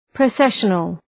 Προφορά
{prə’seʃənəl}